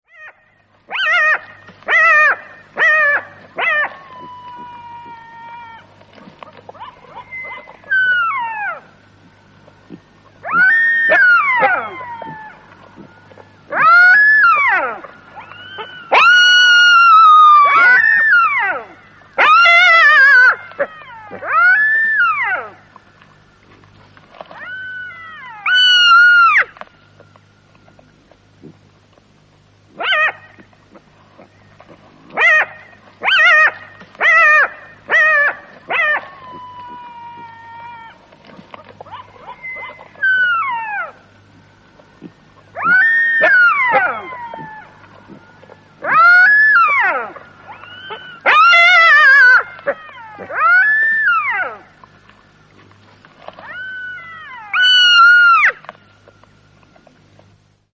Чепрачный шакал у добычи, поедая ее, зовет сородичей